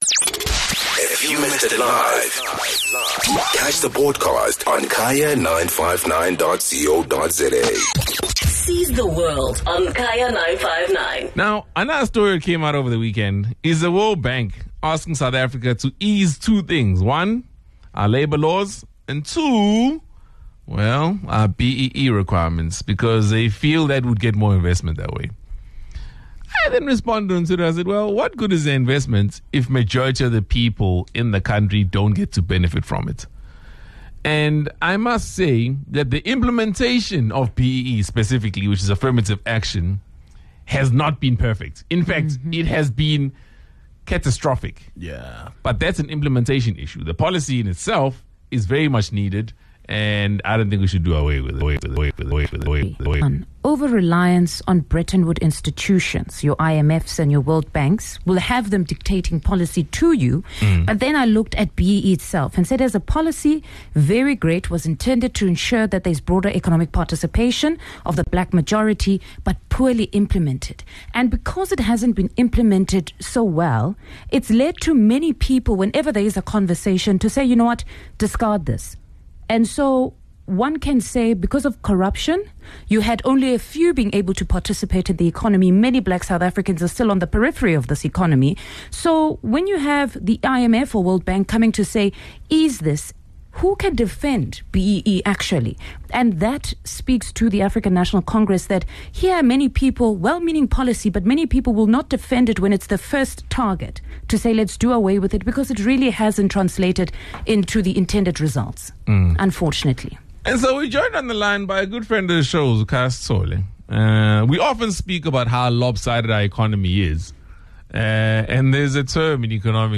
Listeners were engaged on whether we should prioritize investment over labour laws and equitable share in the economy, or take care of people first, domestically and then investments will come later if needed.